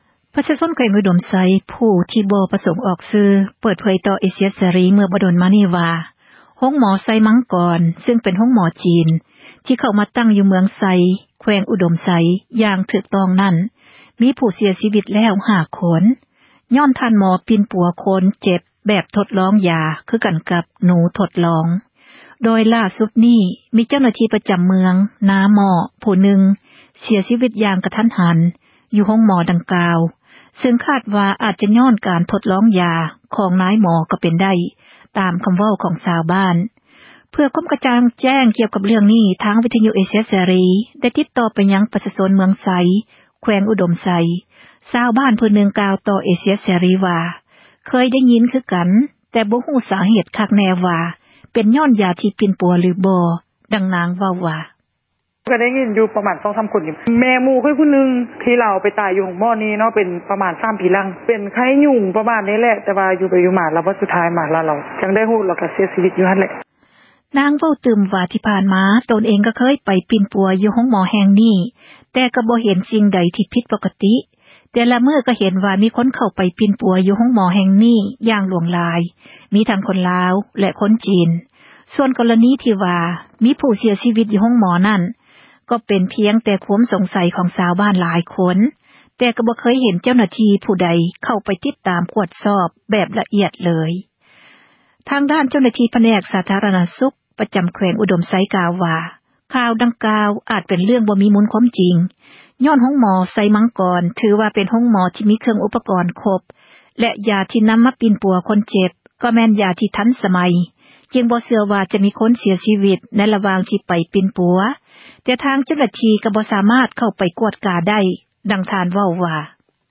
ເພື່ອຄວາມ ກະຈ່າງແຈ້ງ ກ່ຽວກັບ ເຣື້ອງນີ້ ທາງ ວິທຍຸ ເອເຊັຽ ເສຣີ ໄດ້ ຕິດຕໍ່ ໄປຍັງ ປະຊາຊົນ ເມືອງໄຊ ແຂວງ ອຸດົມໄຊ, ມີ ຊາວບ້ານ ຜູ້ນຶ່ງ ກ່າວຕໍ່ ເອເຊັຽ ເສຣີ ວ່າ ເຄີຍ ໄດ້ຍິນ ຄືກັນ ແຕ່ບໍ່ຮູ້ ສາເຫດ ຄັກແນ່ວ່າ ເປັນຍ້ອນ ຢາ ທີ່ ປິ່ນປົວ ຫຼືບໍ່?